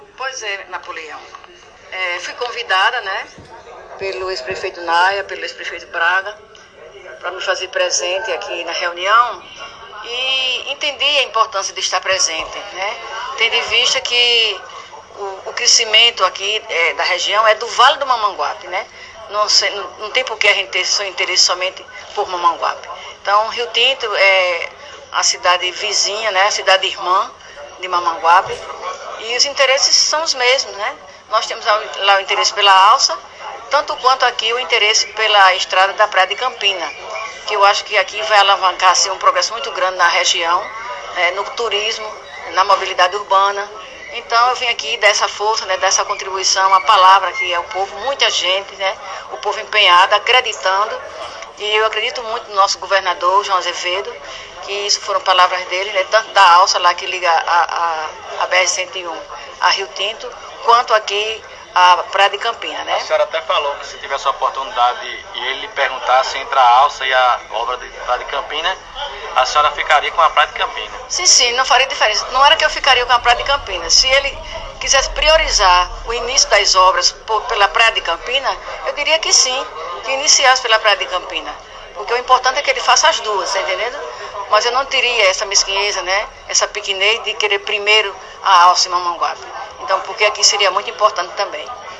A declaração foi dada durante entrevista à imprensa da região, após reunião com lideranças políticas e comunitárias em Praia de Campina.